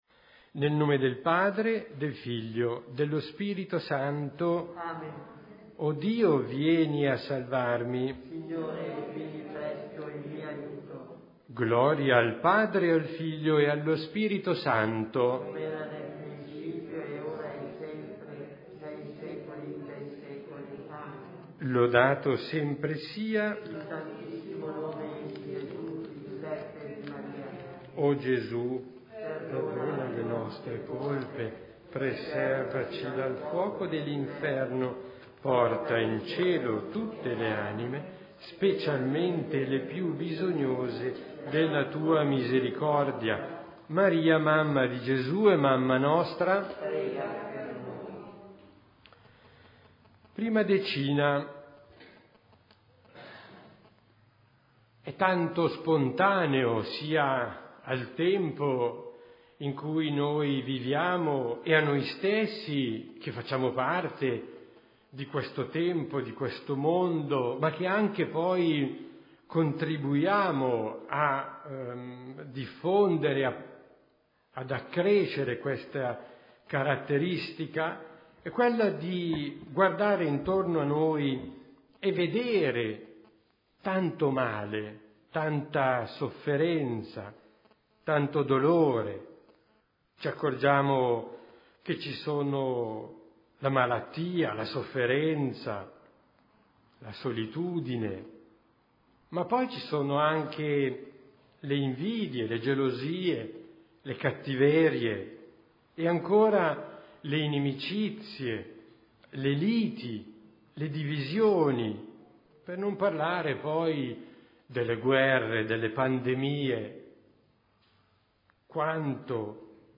Santo Rosario